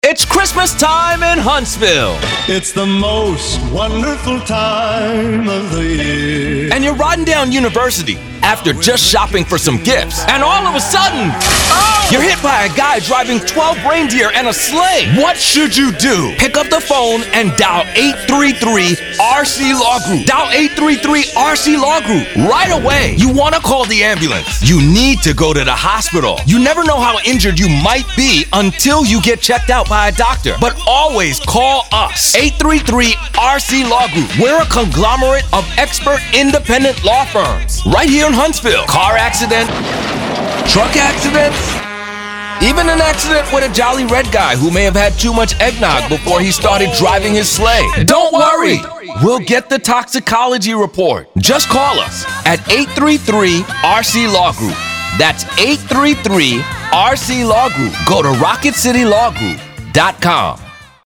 Humorous Christmas Attorney Ad